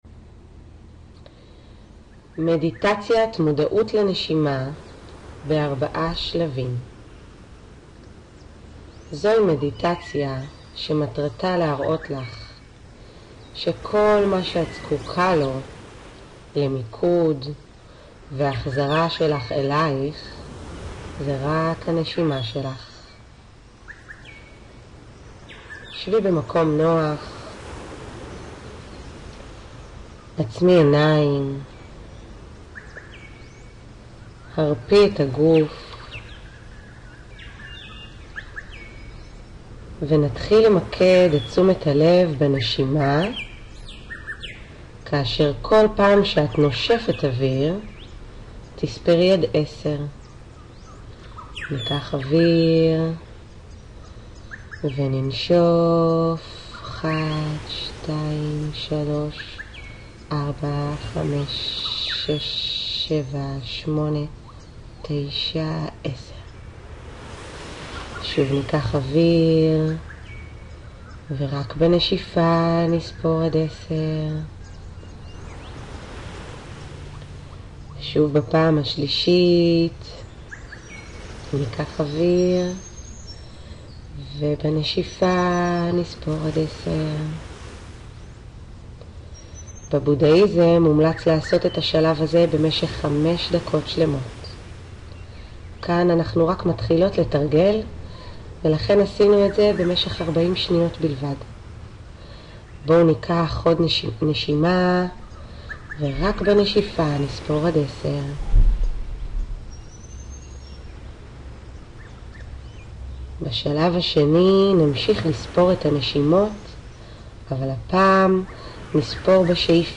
מדיטצית מיינדפולנס למודעות הנשימה ב- 4 שלבים:
מדיטציית+מודעות+לנשימה+4+שלבים.mp3